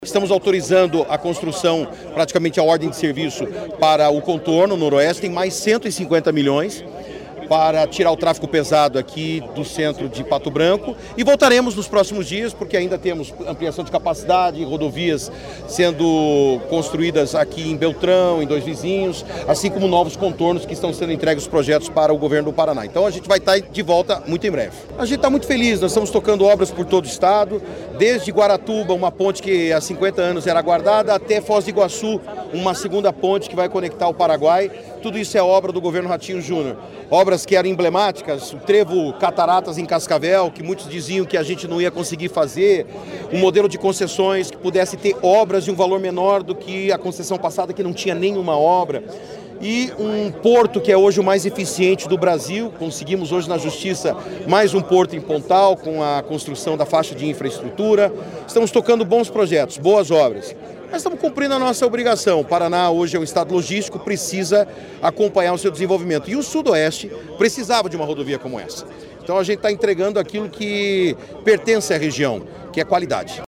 Sonora do secretário de Infraestrutura e Logística, Sandro Alex, sobre o início das obras da nova etapa do Contorno de Pato Branco